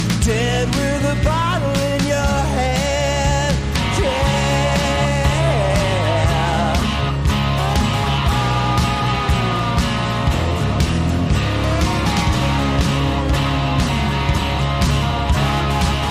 A healthy handful of chords, tautly played.
blistering blues-rocker